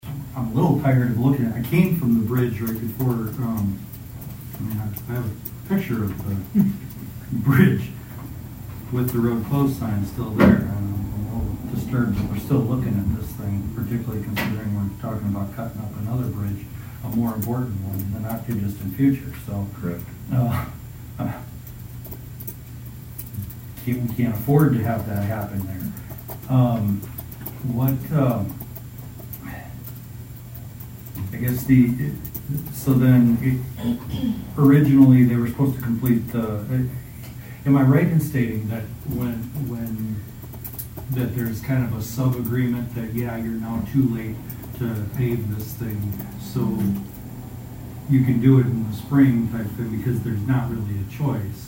At Monday night’s City Council meeting, one councilman expressed frustration with the progress on the bridge was Councilman Talmage Ekanger.